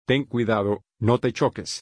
crashes.mp3